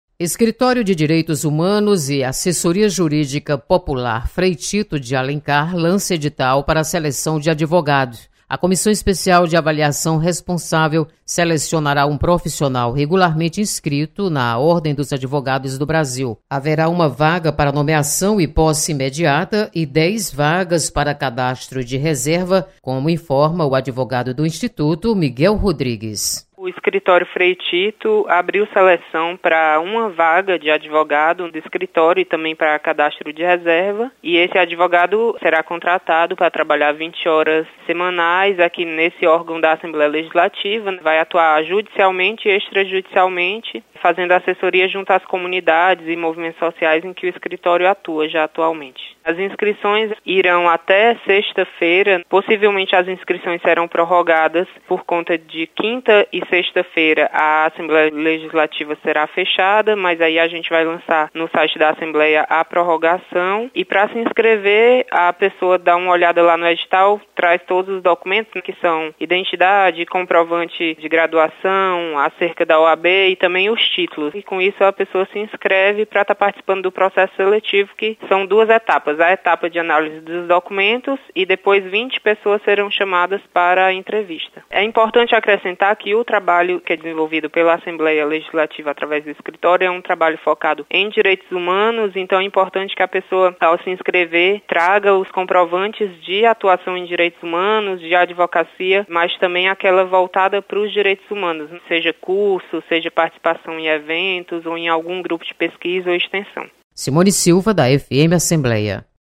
Escritório Frei Tito lança edital para seleção de advogado. Repórter